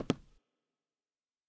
FootstepW3Left-12db.wav